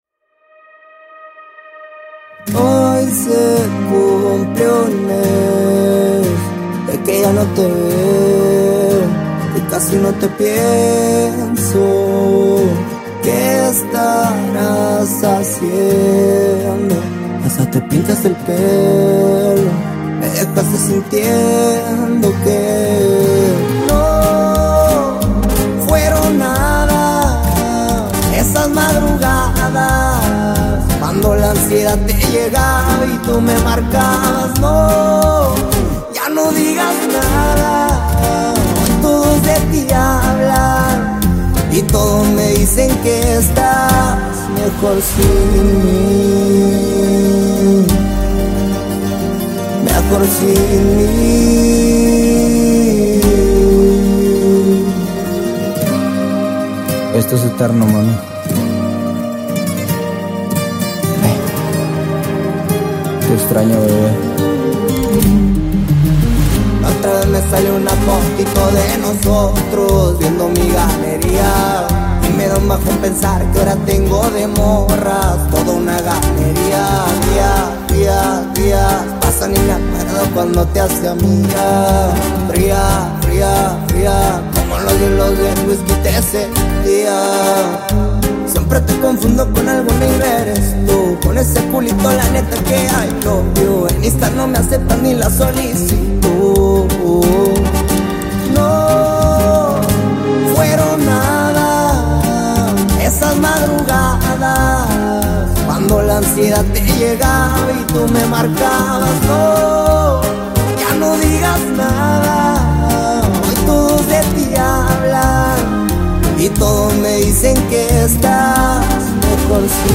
a smooth and engaging tune